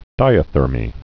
(dīə-thûrmē)